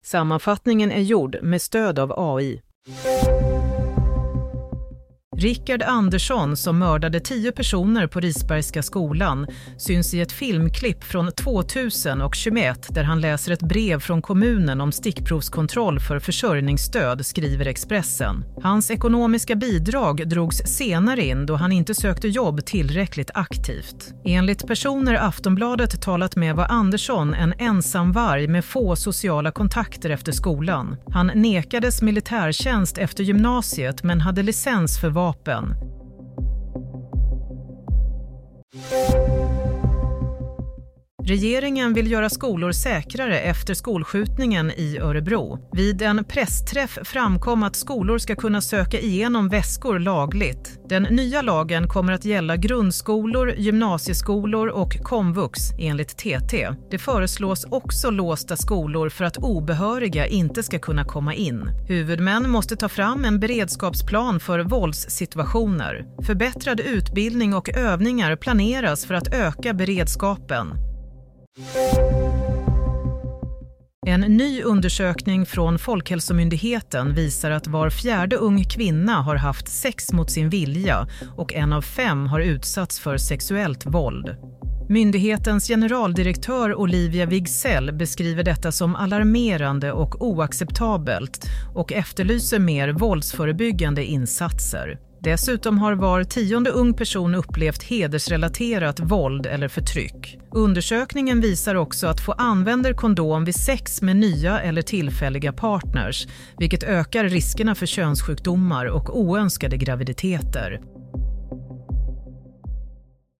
Sammanfattningen av följande nyheter är gjord med stöd av AI.